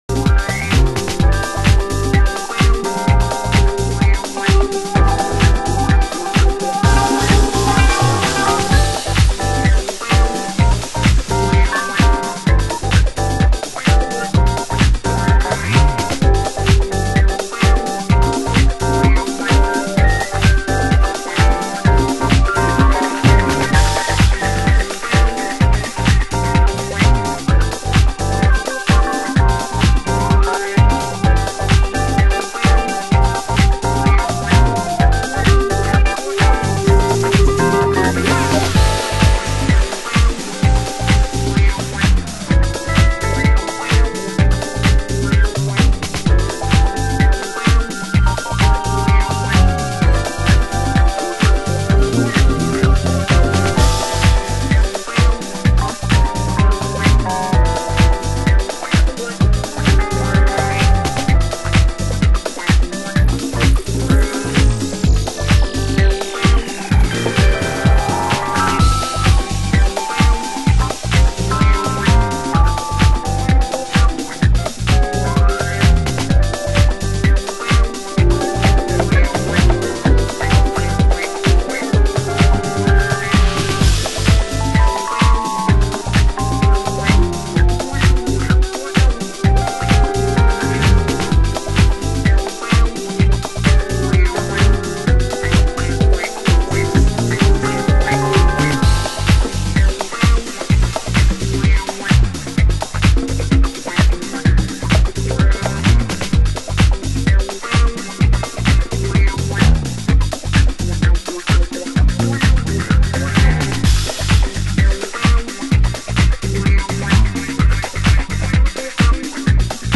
盤質：少しチリパチノイズ有/ラベルに少しシミ汚れ有に少しシミ汚れ有　　ジャケ：底部4センチ割れ